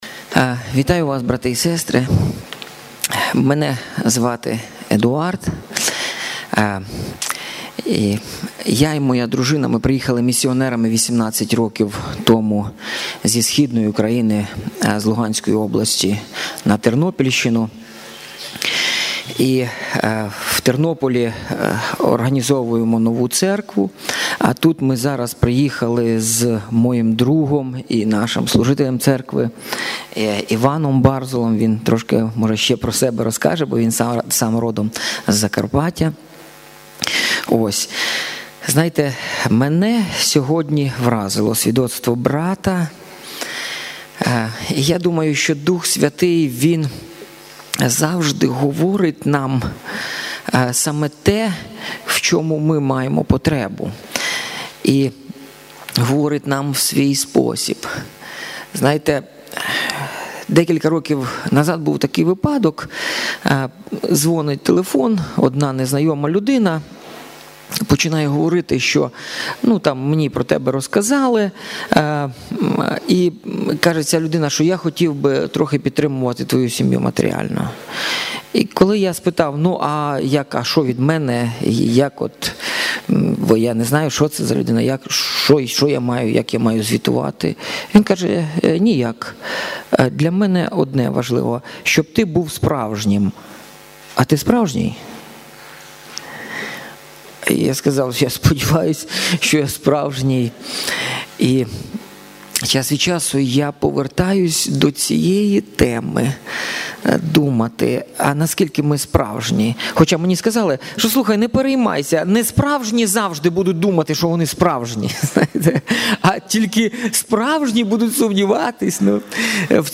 Фрагмент богослужіння 27 травня 2018 року